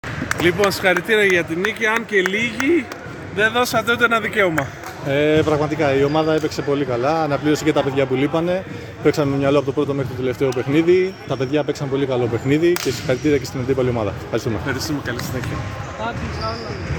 GAME INTERVIEWS
Παίκτης Eurobank